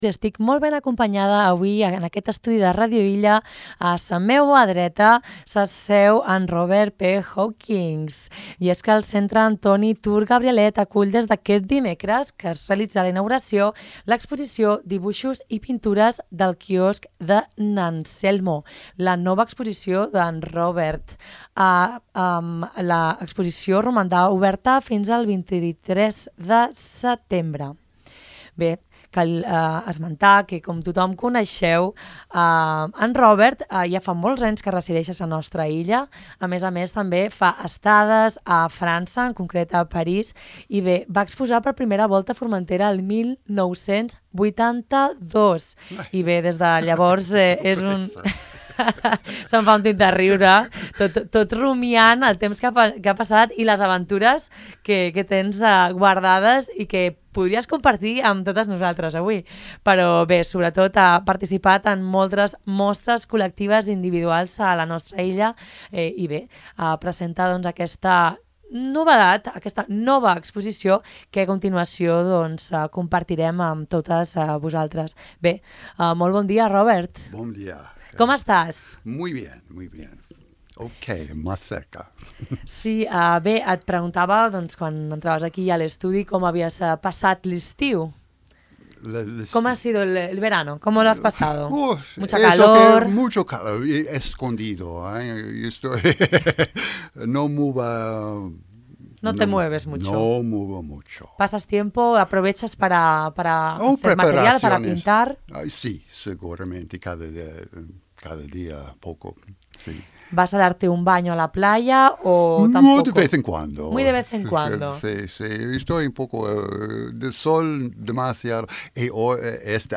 Escoltau l’entrevista sencera a Ràdio Illa.